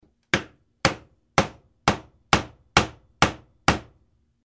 Звуки молотка судьи
На этой странице собраны звуки молотка судьи — от четких одиночных ударов до протяжных стуков.
Звук ударов молотка